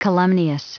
Prononciation du mot calumnious en anglais (fichier audio)